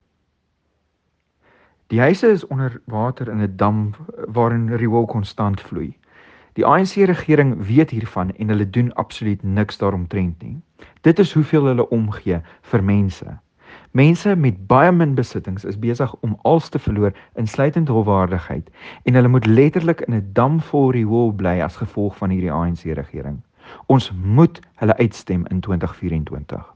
Afrikaans soundbites by George Michalakis MP and